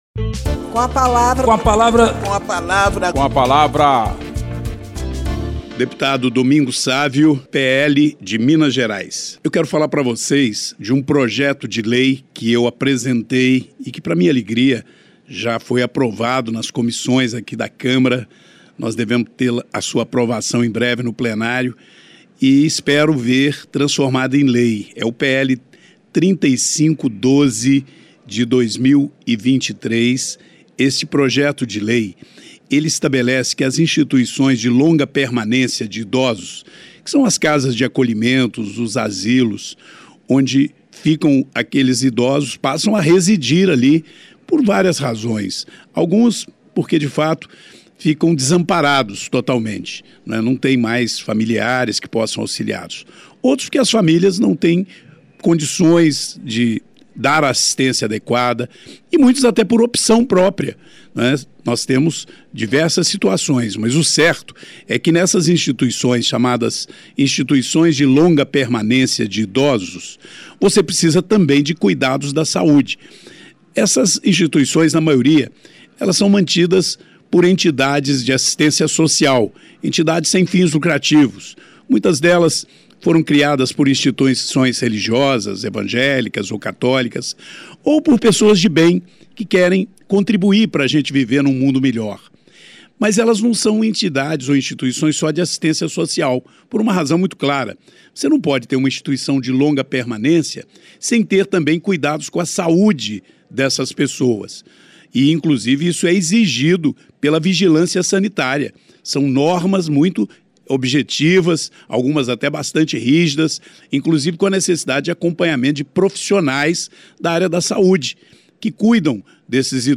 O deputado Domingos Sávio (PL-MG) ressalta a importância do projeto que apresentou para definir as Instituições de Longa Permanência para Idosos (ILPIs) como entidades da área da saúde, hoje, consideradas instituições de assistência social. Na prática, o projeto - que está pronto para análise do Plenário da Câmara - permite que ILPIs públicas e filantrópicas recebam recursos de emendas parlamentares destinadas tanto para a assistência social quanto para a saúde.
Espaço aberto para que cada parlamentar apresente aos ouvintes suas propostas legislativas